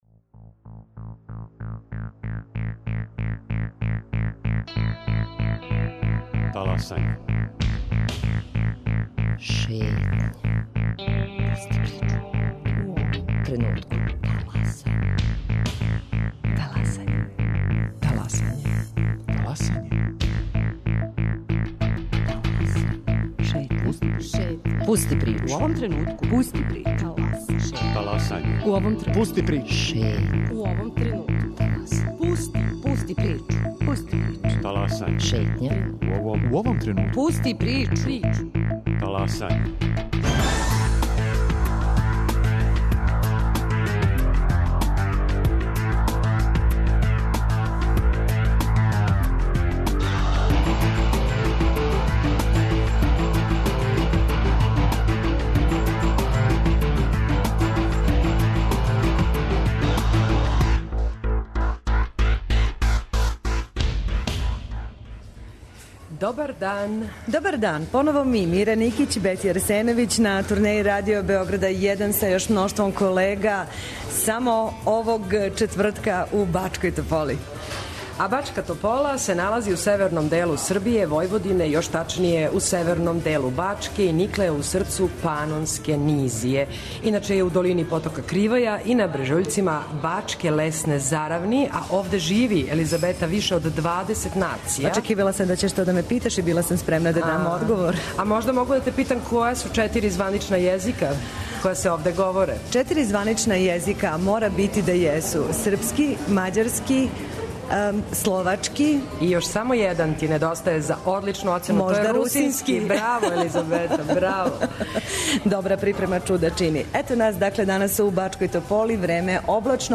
коју емитујемо уживо из Бачке Тополе